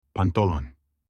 pants-in-turkish.mp3